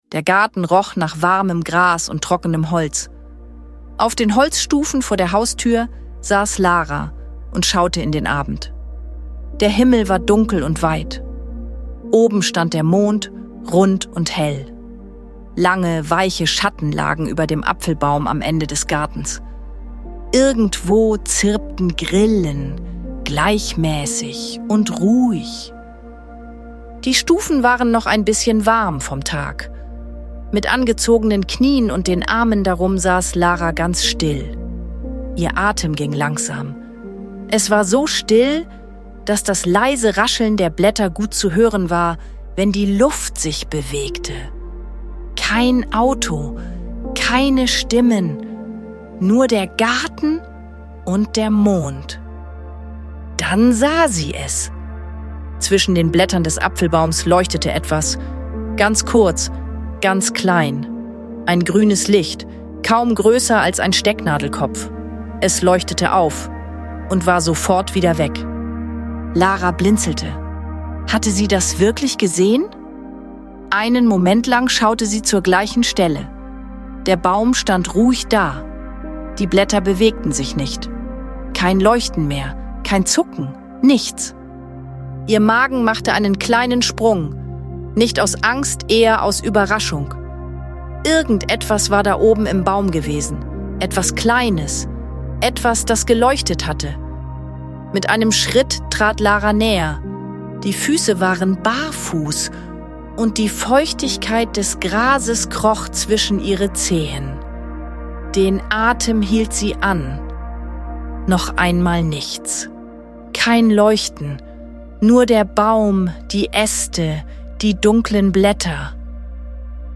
Neugierig und vorsichtig folgt sie dem geheimnisvollen Licht – und erlebt einen besonderen Moment voller Ruhe und Vertrauen. Diese sanfte Geschichte begleitet Kinder in eine ruhige Abendstimmung und lädt zum Entspannen ein. Die warme Atmosphäre, die leisen Naturgeräusche und die behutsame Begegnung schaffen Geborgenheit und helfen beim Einschlafen.